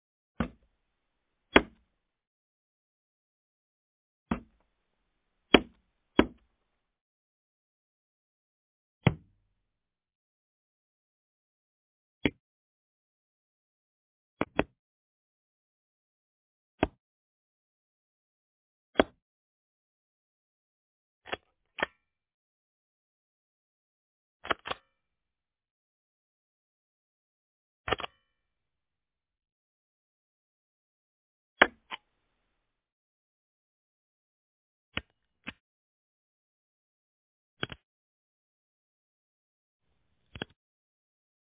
SFX敲章(盖章的声音)音效下载
SFX音效